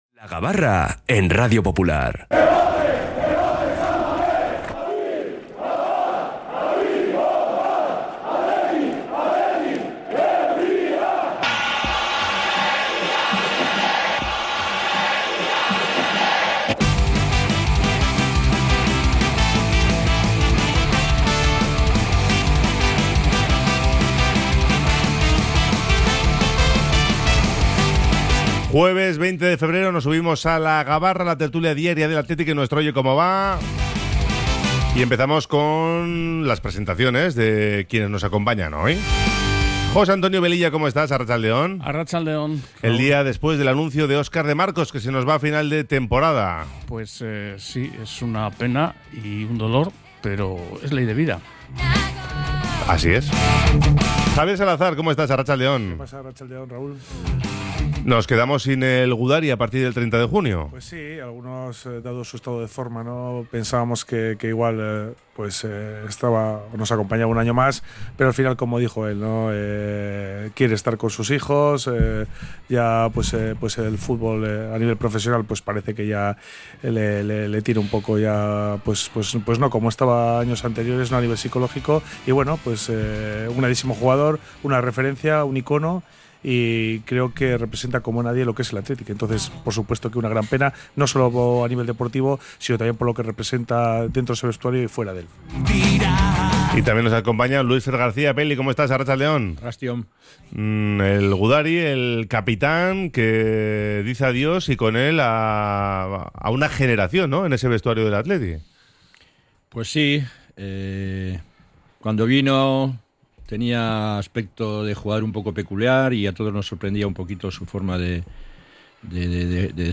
la participación de los oyentes